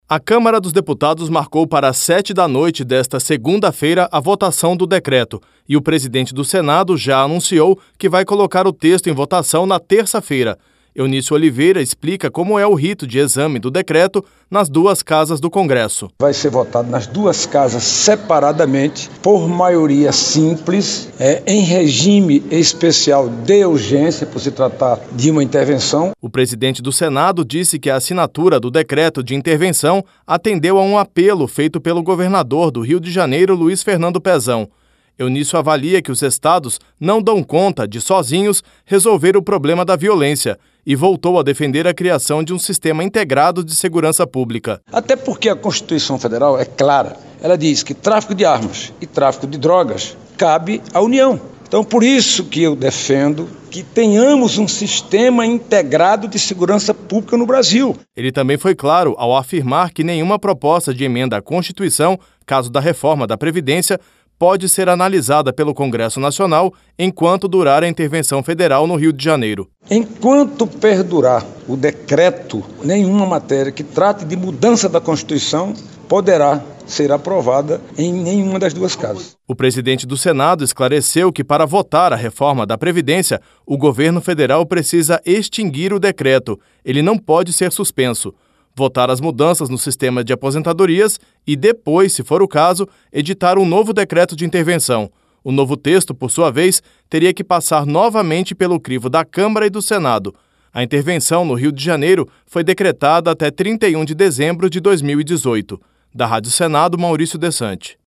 O presidente do Senado, Eunício Oliveira, disse nesta sexta-feira (16) que o decreto de intervenção federal na segurança pública do estado do Rio de Janeiro será votado com a maior rapidez possível.